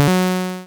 disarm_success.wav